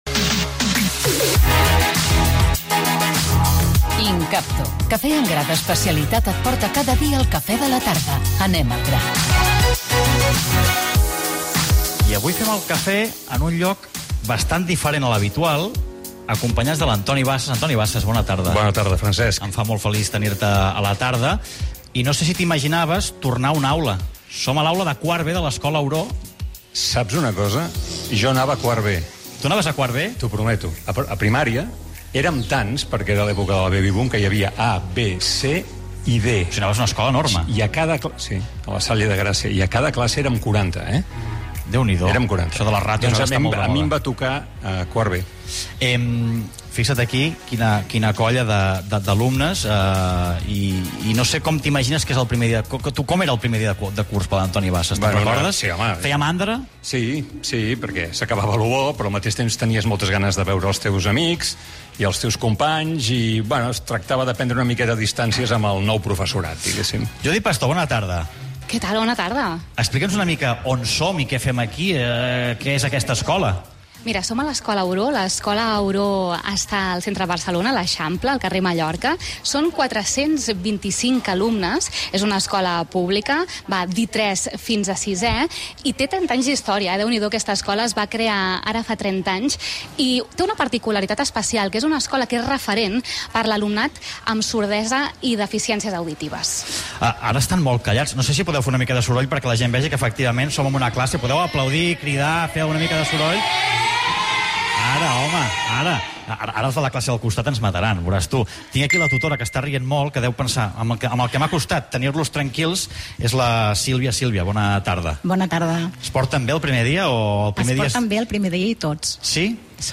9970e33d73e0ebee42f8d78e125368eee77b151f.mp3 Títol Catalunya Ràdio Emissora Catalunya Ràdio Cadena Catalunya Ràdio Titularitat Pública nacional Nom programa La tarda de Catalunya Ràdio Descripció Espai fet des de l'Escola Auró de l'Eixample de Barcelona amb el periodista Antoni Bassas. Publicitat, records ecolars d'Antoni Bassas, el primer dia de classe, opinions dels infants i de la mestra, una prova d'anglès al presentador i l'invitat.